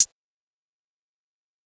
HiHat (33).wav